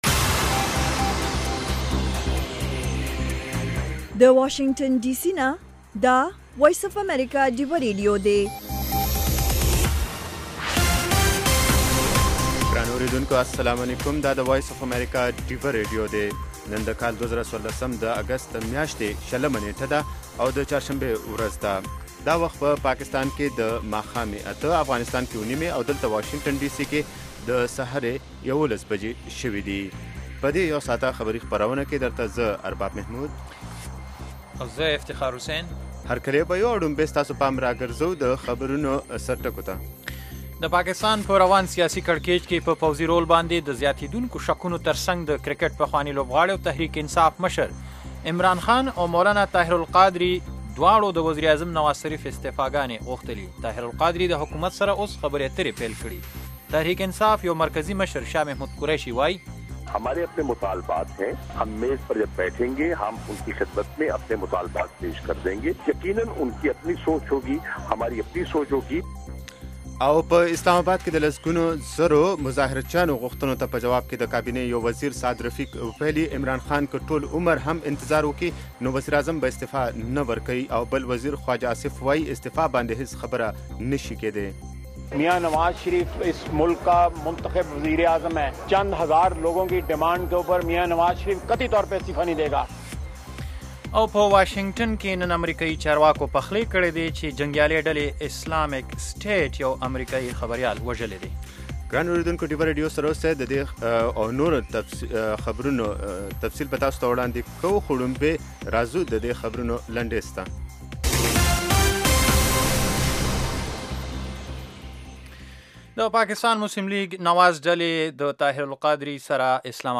خبرونه - 1500